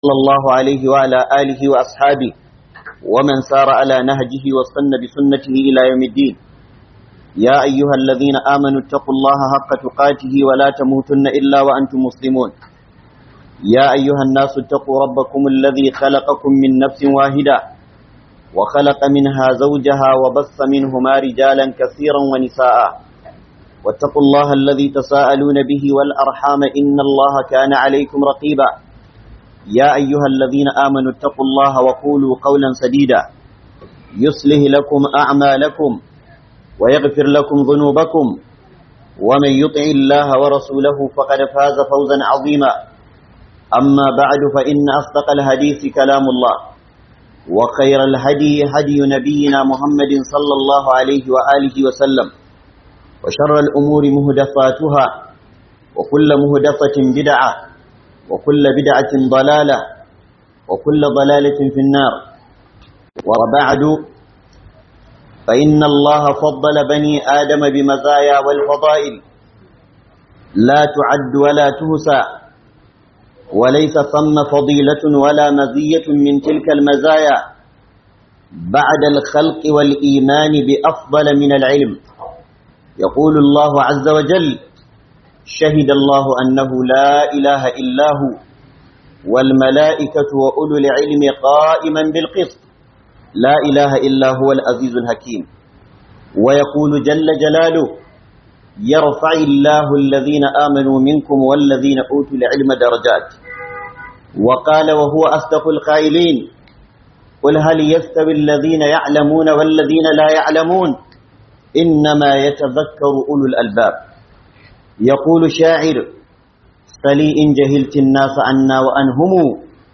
BAMBANCIN ILIMI DA JAHILCI - Huduba